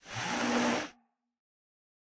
sniffing1.ogg